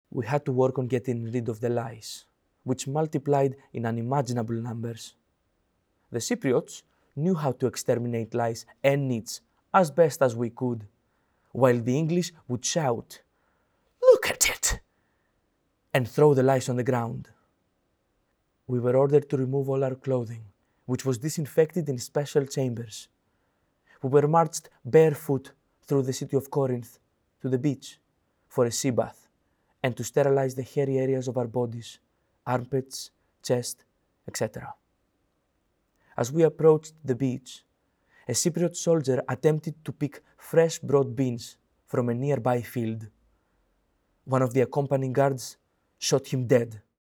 Audio dramatisation adapted from the memoirs of POW, Phylactis Aristokleous.